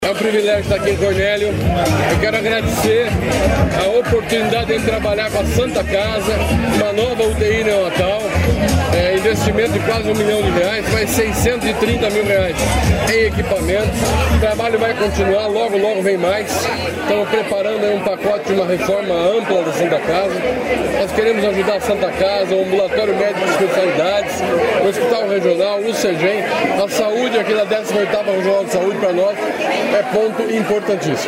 Sonora do secretário Estadual de Saúde, Beto Preto, sobre a nova UTI neonatal da Santa Casa de Cornélio Procópio